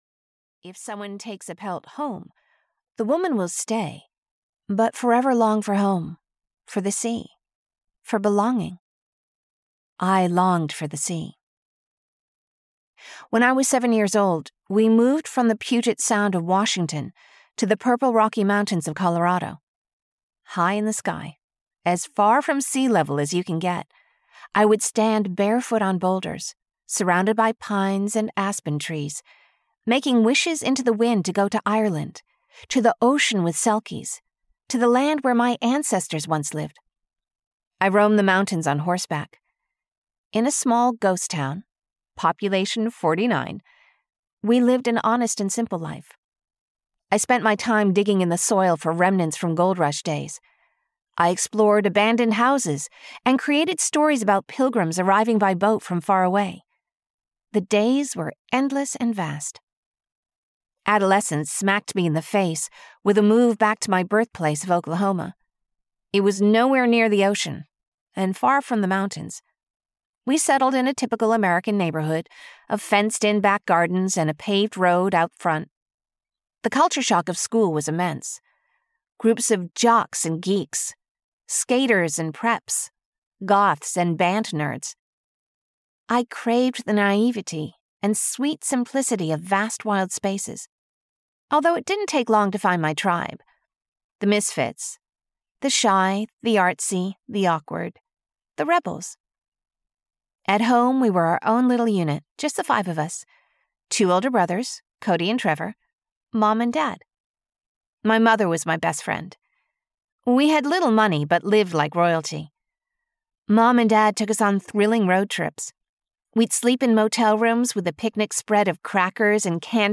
Audio knihaIn Her Shoes: Women of the Eighth (EN)
Ukázka z knihy